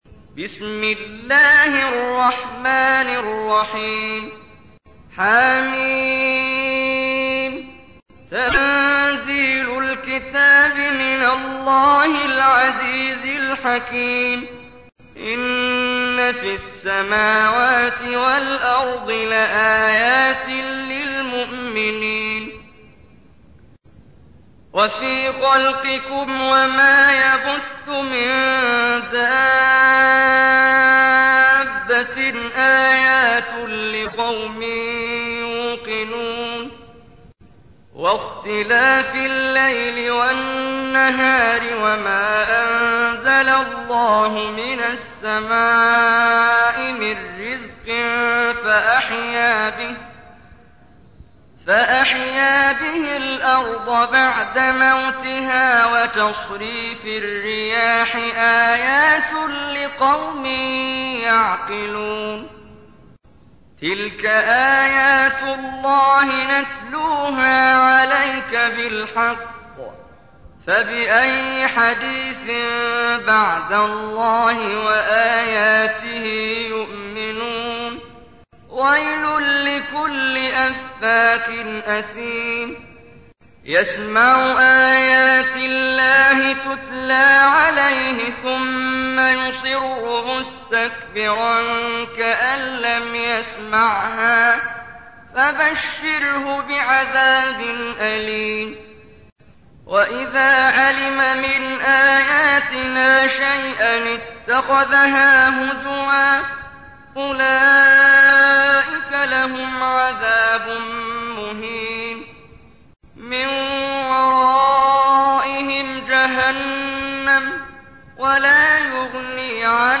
تحميل : 45. سورة الجاثية / القارئ محمد جبريل / القرآن الكريم / موقع يا حسين